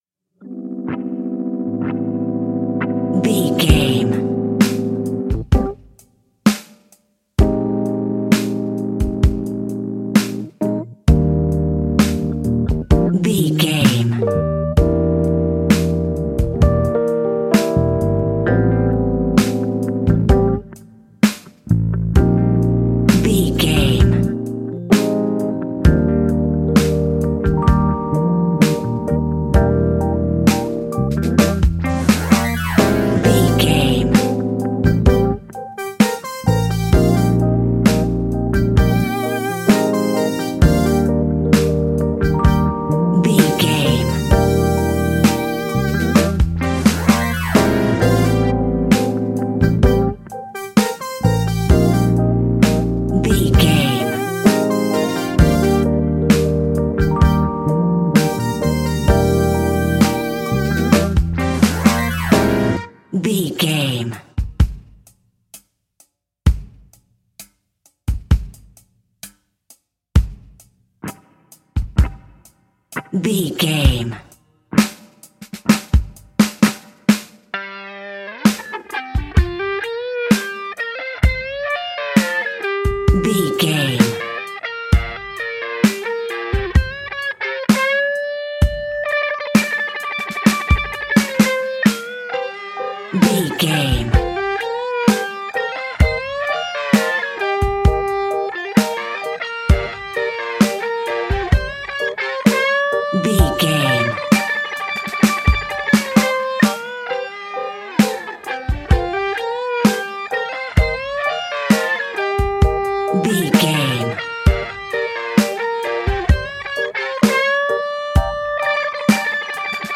Fast paced
In-crescendo
Uplifting
Ionian/Major
hip hop